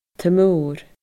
taigh-mór /təˈmoːr/